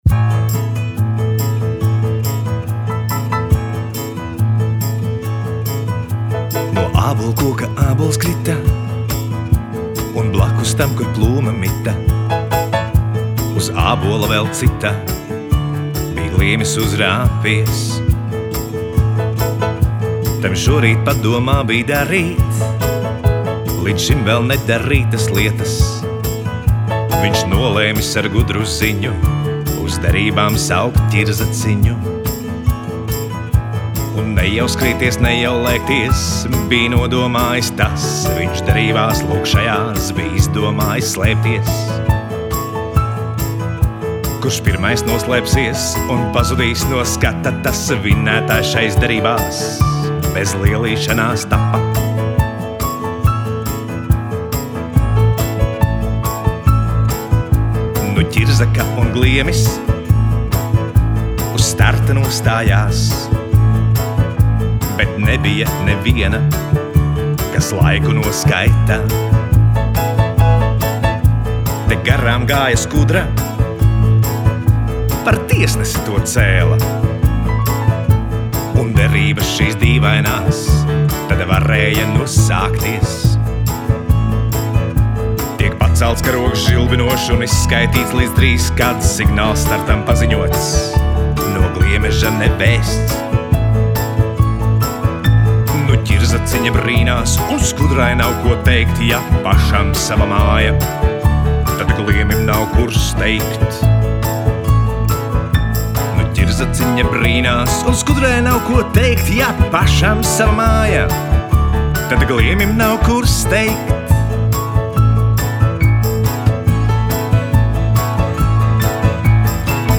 Dziesmas un dziesmu pavadījumi.
taustiņi
ģitāra
perkusijas.